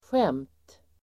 Uttal: [sjem:t]